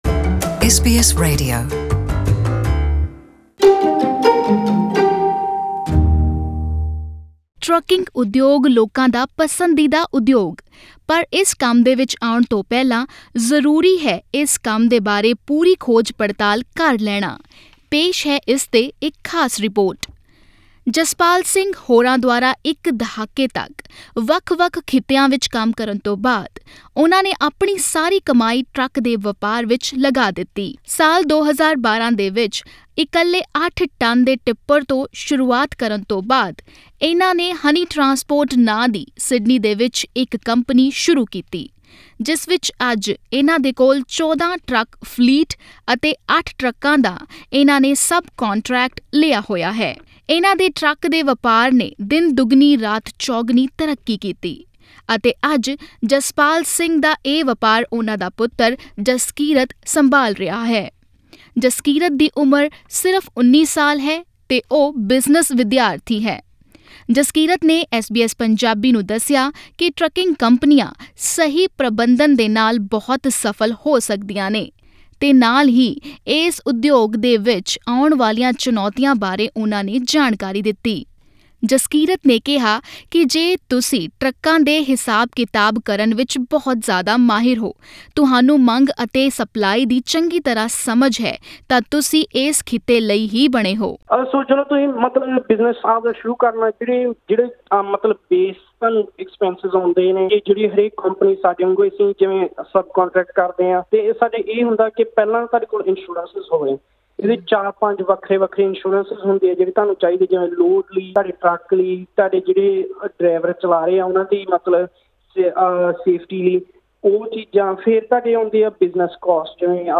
ਕੀ ਆਪਣਾ ਟਰੱਕ ਚਲਾਉਣਾ ਬੇਹਤਰ ਹੈ ਜਾਂ ਕੰਪਨੀ ਦਾ ਟਰੱਕ ਚਲਾਉਣਾ? ਇਸ ਆਡੀਓ ਰਿਪੋਰਟ ਵਿੱਚ ਸੁਣੋ ਇਸ ਸਨਅਤ ਨਾਲ਼ ਸਬੰਧਿਤ ਕੁਝ ਜ਼ਰੂਰੀ ਗੱਲ਼ਾਂ....